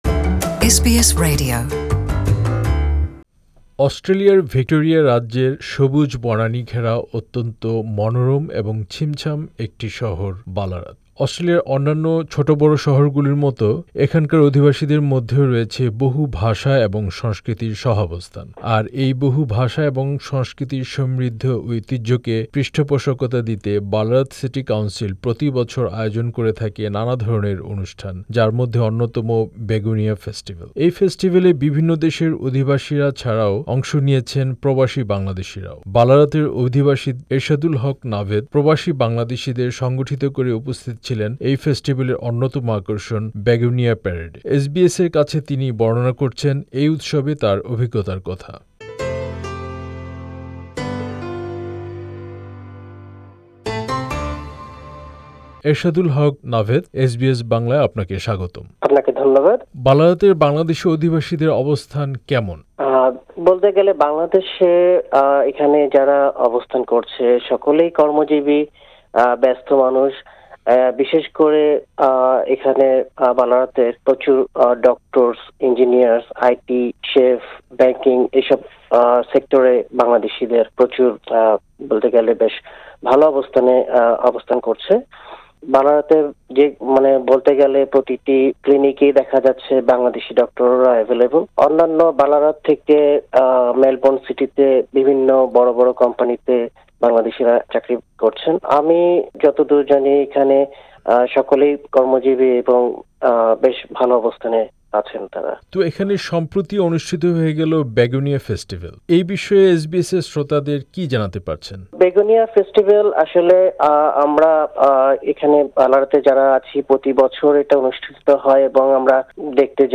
সাক্ষাৎকারটি বাংলায় শুনতে উপরের ছবিতে বাঁ থেকে নিচে প্লে বাটনে ক্লিক করুন।